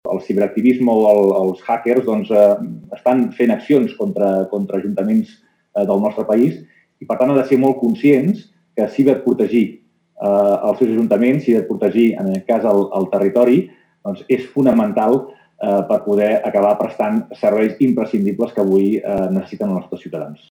Escoltem el conseller de Polítiques Digitals i Administració Pública, Jordi Puigneró.